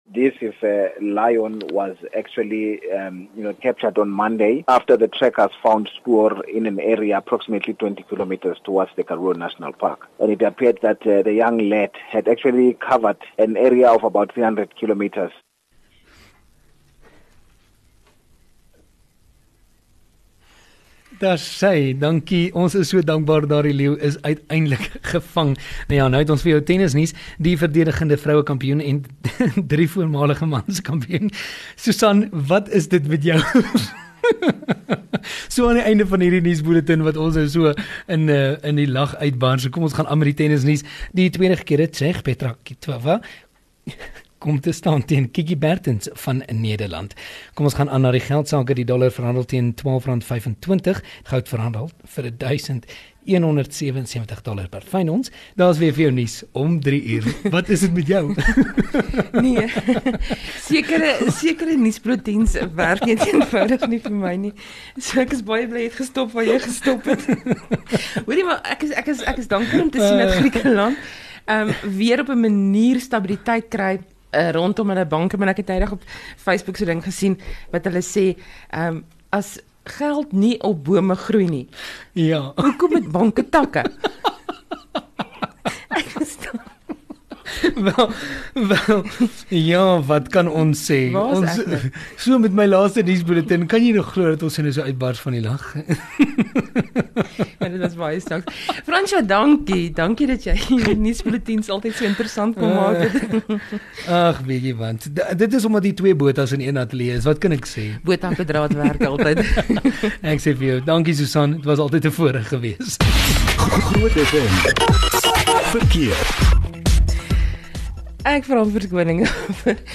lag haarself 'n papie tydens 30 Junie 2015 se 13h00 nuus.
View Promo Continue MUT Radio Install GROOT Oepsies 1 Aug GROOT oepsie